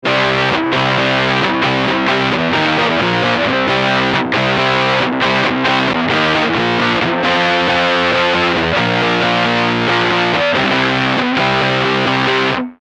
A heavy, thick fuzz with a vintage sound.
guitar - effect - cabinet simulator - sound card (software reverb)